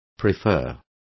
Complete with pronunciation of the translation of prefer.